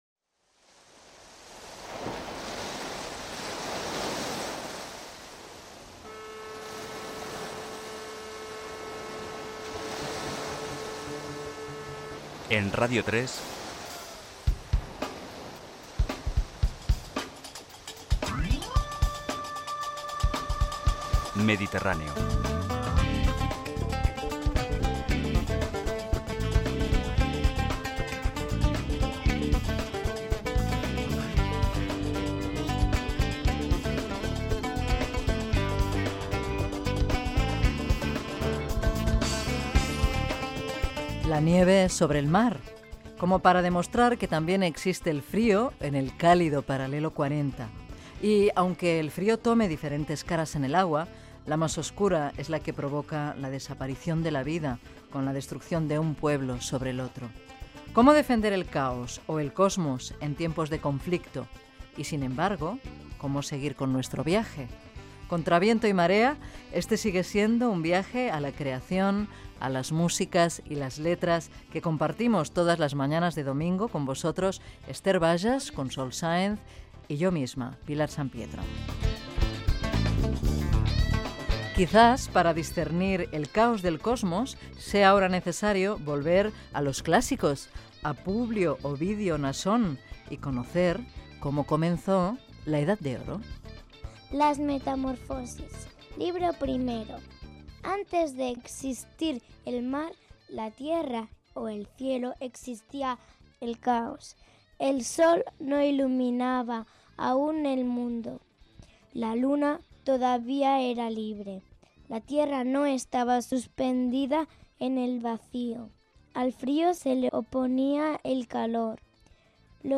Careta del programa, presentació, equip, lectura d'un fragment de "Les metamorfosis" d'Ovidi
espai musical